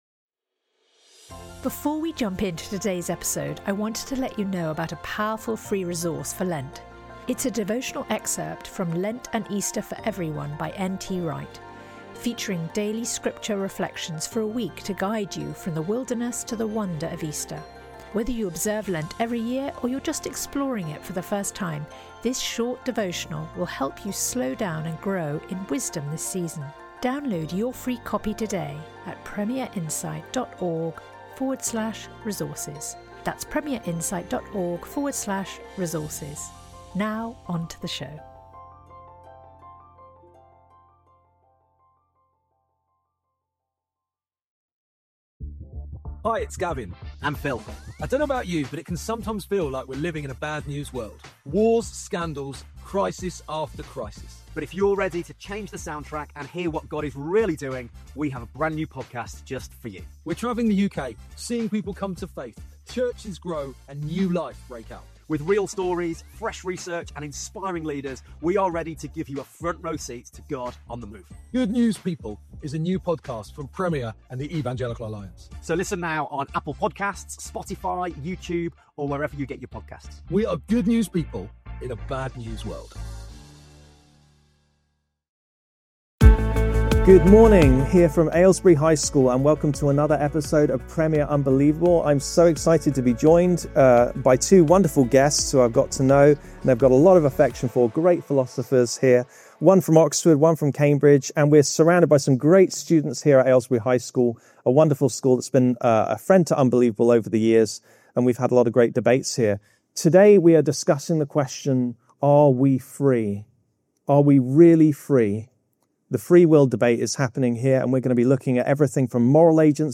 Recorded at Aylesbury High School
a lively Unbelievable? Podcast schools debate on one of life’s biggest questions: are we really free?
Expect sharp definitions, real-world examples, and brilliant student questions starting @33:34 mins in.